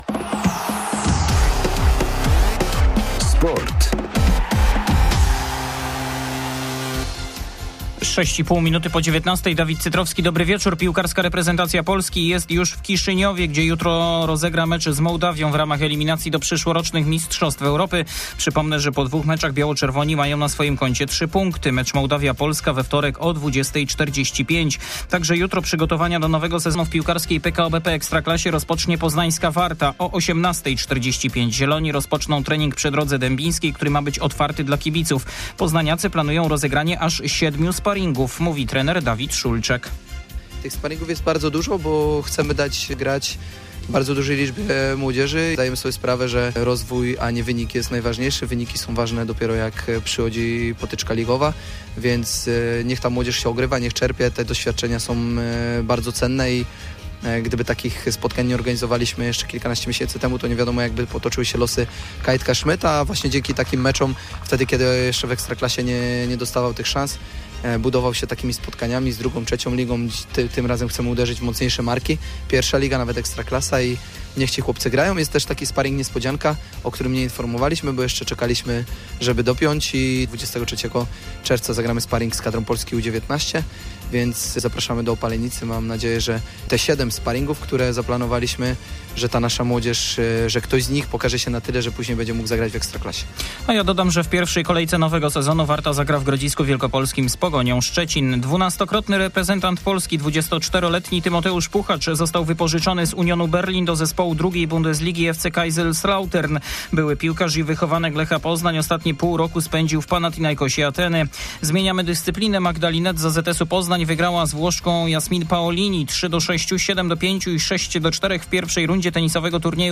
19.06.2023 SERWIS SPORTOWY GODZ. 19:05